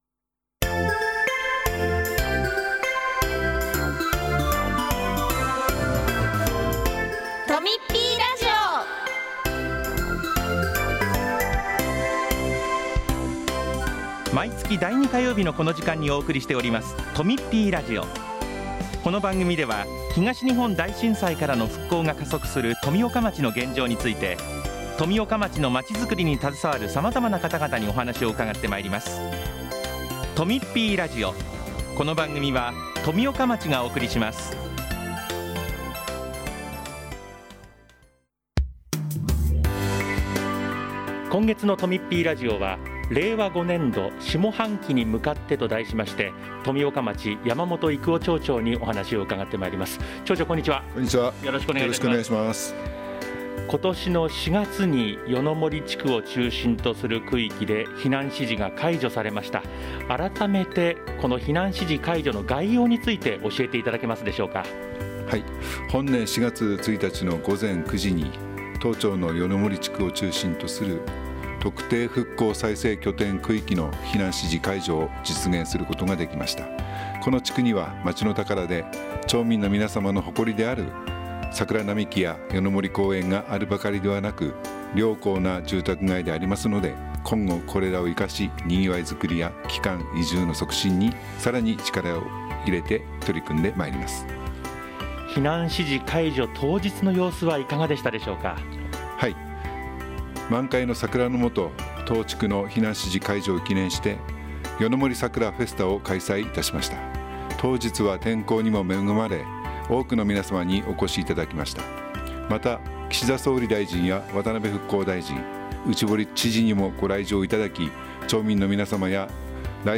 今回は、山本育男町長が「下半期に向かって」というテーマで4月1日の夜の森地区等の避難指示の解除や帰還困難区域の再生に向けた取り組み、下半期のイベント予定などをお話ししています。